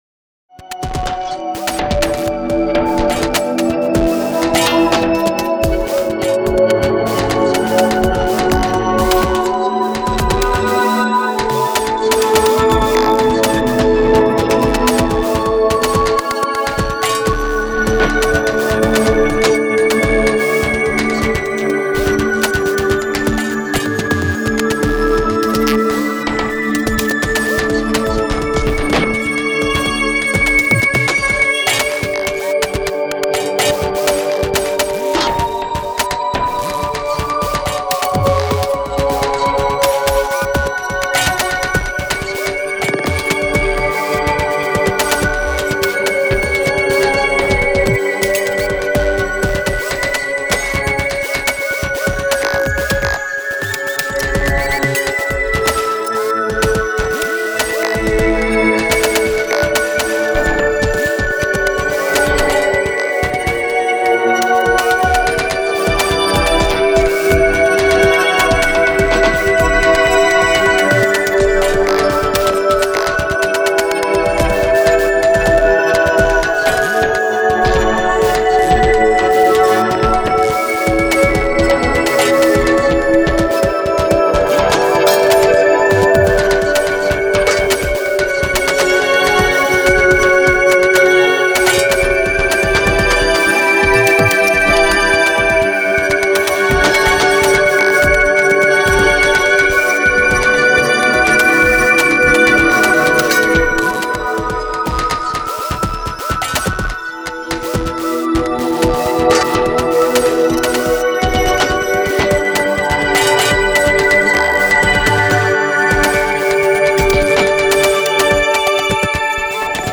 ロング暗い激しい